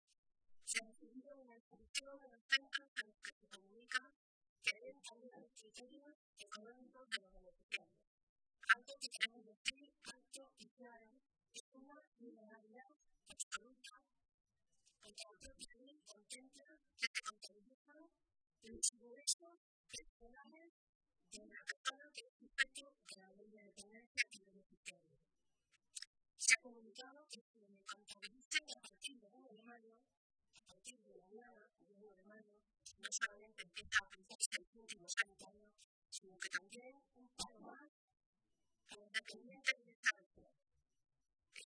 Guadalupe Martín, diputada Nacional del PSOE de Castilla-La Mancha
Cortes de audio de la rueda de prensa